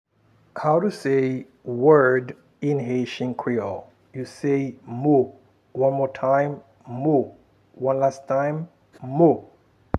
Pronunciation and Transcript:
How-to-say-word-in-haitian-creole-Mo-pronunciation.m4a